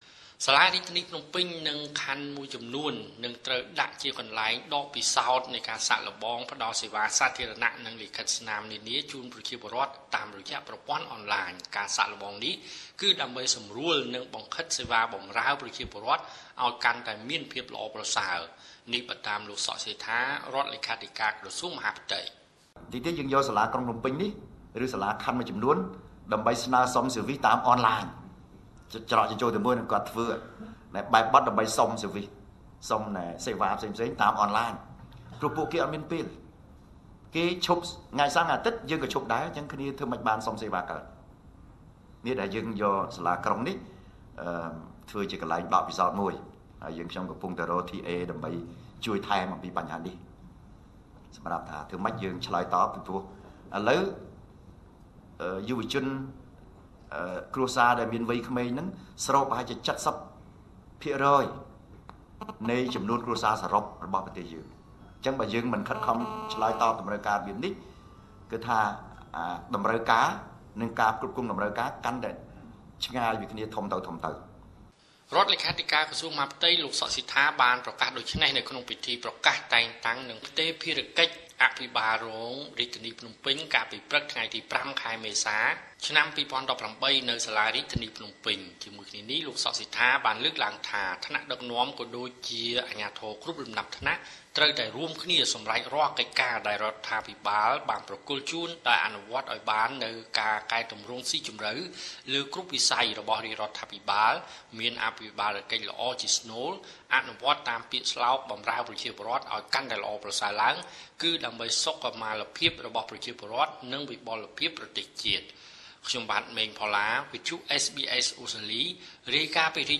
(សំឡេង លោក សក់ សេដ្ឋា ) រដ្ឋលេខាធិការក្រសួងមហាផ្ទៃ លោកសក់សេដ្ឋា បានប្រកាសដូច្នេះ ក្នុងពិធីប្រកាសតែងតាំង និងផ្ទេរភារកិច្ចអភិបាលរងរាជធានីភ្នំពេញ កាលពីព្រឹកថ្ងៃទី០៥ មេសា ឆ្នាំ២០១៨ នៅសាលារាជធានីភ្នំពេញ។